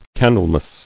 [Can·dle·mas]